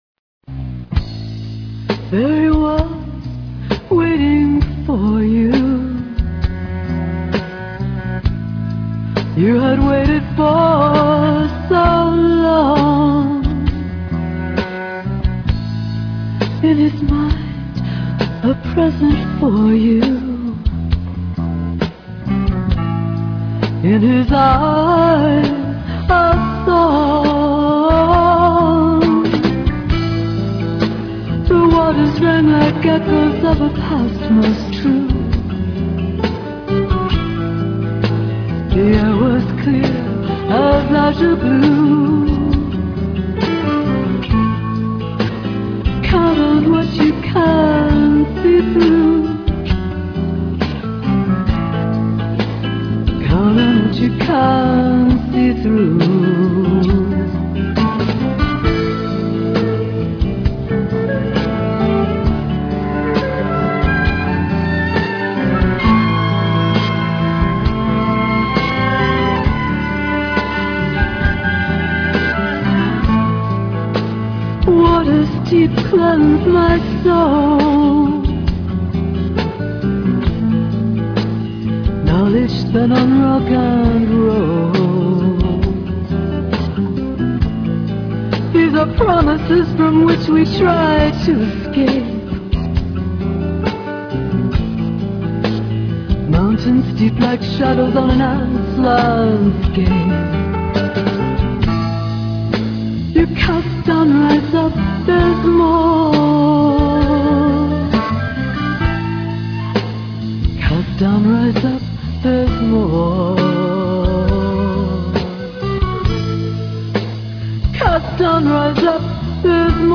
Real Audio/mono